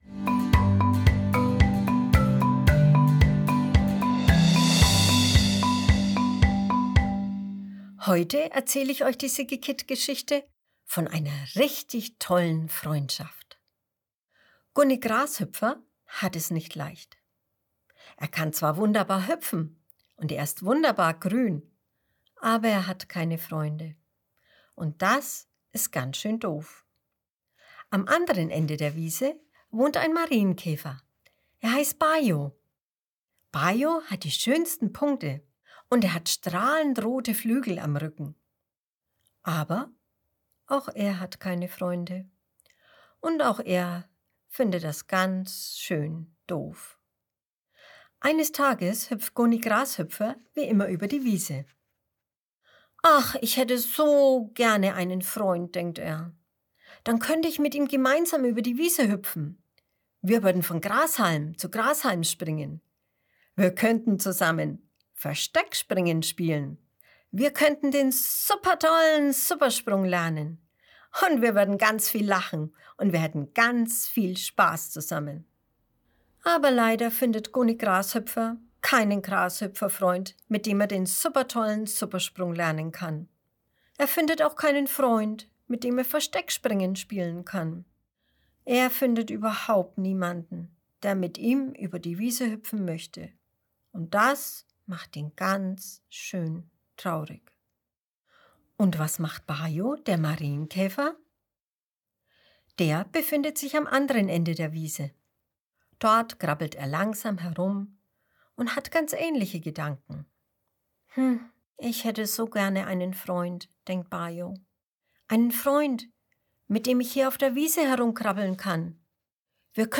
April 2021 Kinderblog Vorlesegeschichten Gunni Grashüpfer ist traurig.